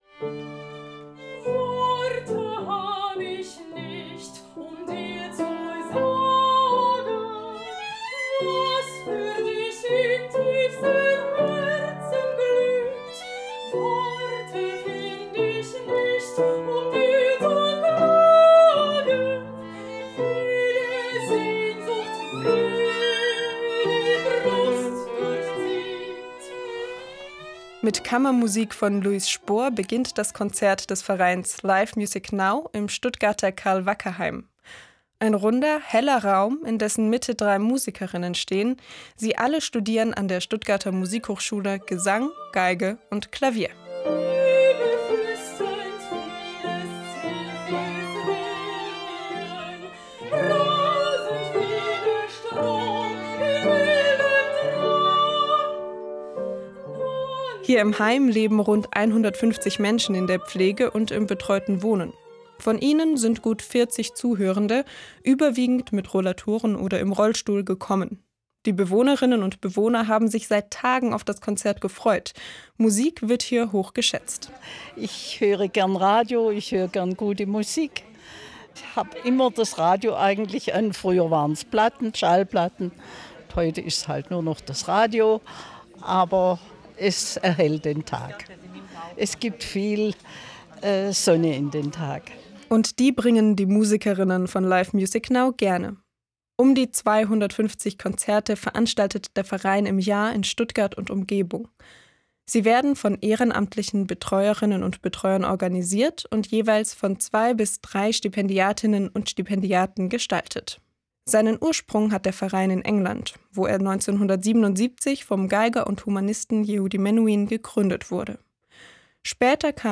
Konzert im Pflegeheim
Ein runder, heller Raum, in dessen Mitte drei Musikerinnen stehen.